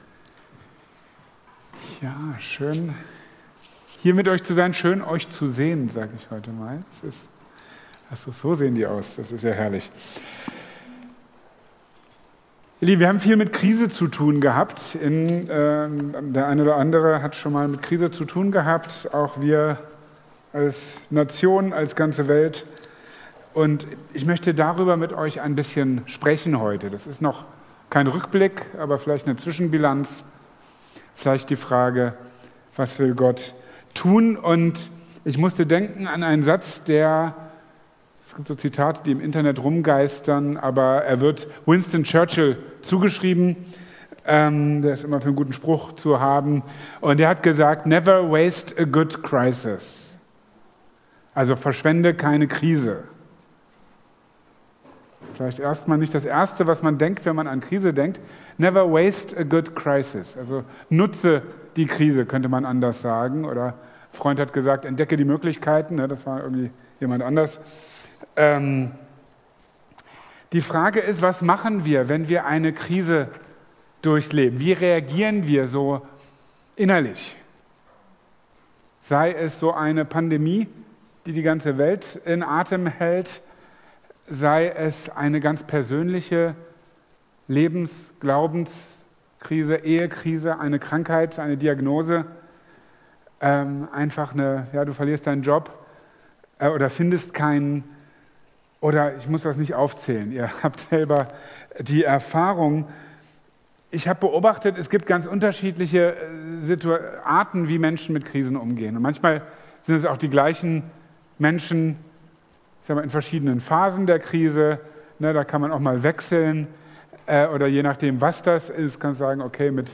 Marburger Predigten